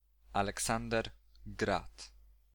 Aleksander Grad [alɛˈksandɛr ˈɡrat]